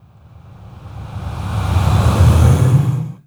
SIGHS 1REV-R.wav